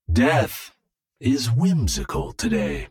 Welp that voice line say it all, especialy if you spam it after first word XD
Zenyatta_-_Death_is_whimsical_today.ogg